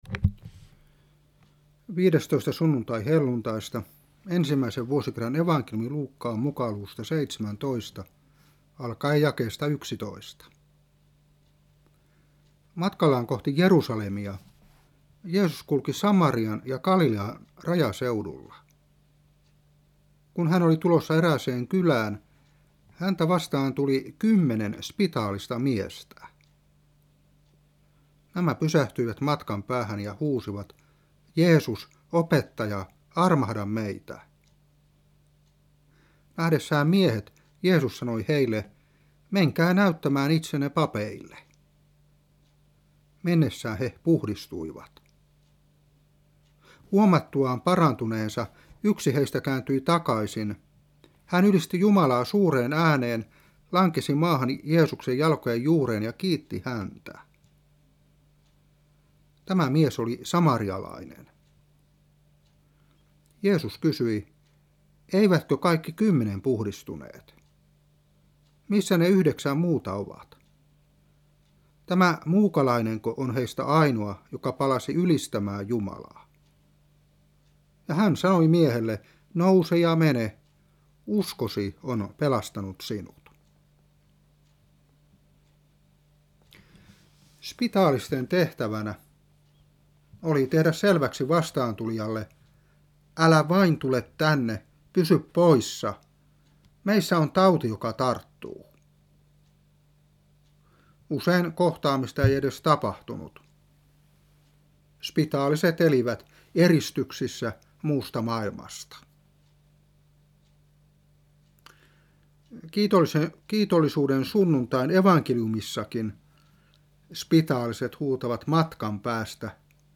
Saarna 2010-9.